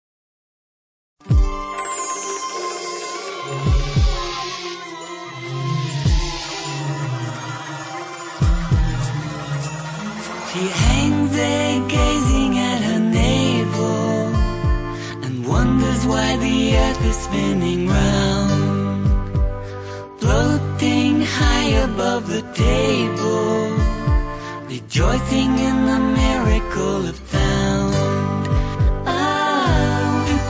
quirky idiosyncratic numbers